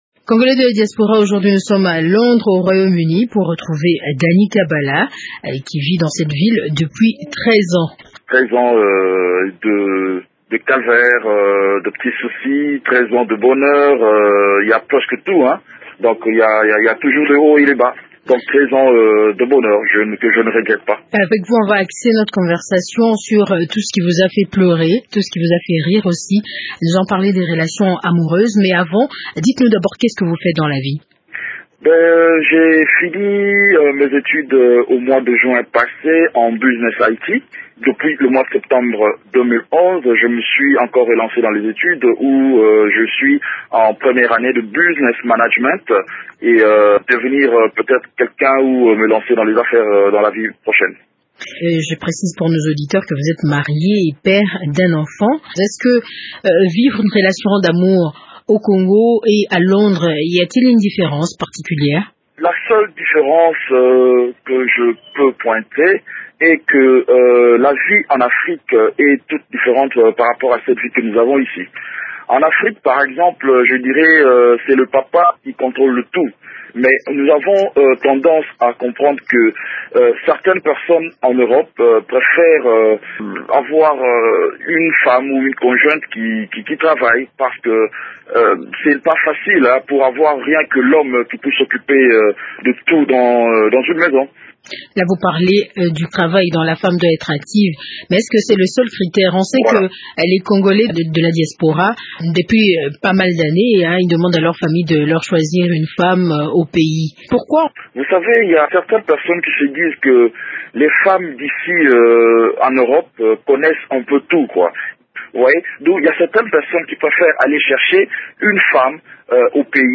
Dans cet entretien, il tente d’expliquer pourquoi certains Congolais de la diaspora préfèrent rentrer au pays pour chercher des conjoints. Il commente aussi les relations que tissent des partenaires congolais qui se rencontrent à l’étranger.